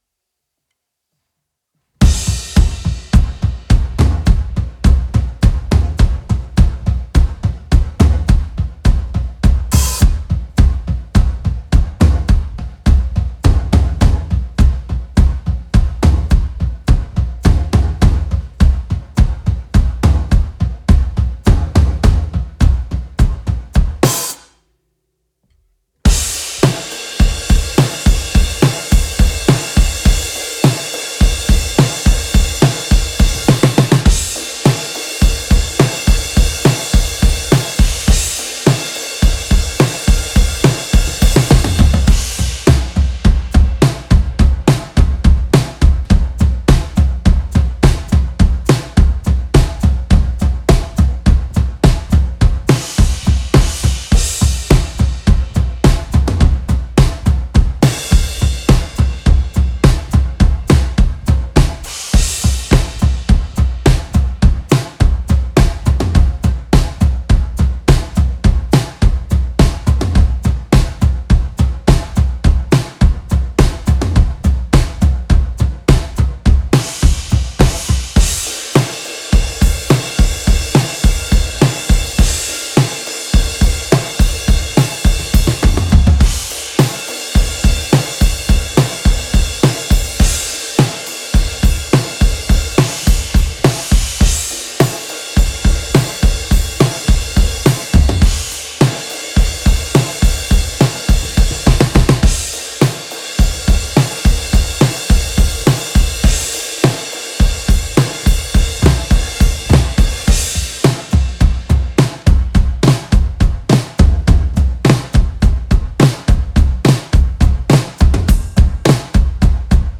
Post Rock
Genre:Post Rock, Grunge
Tempo:209.5 BPM (7/4)
Kit:Rogers 1983 XP8 24"
Mics:14 channels